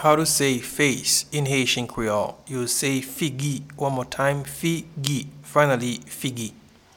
Pronunciation and Transcript:
Face-in-Haitian-Creole-Figi.mp3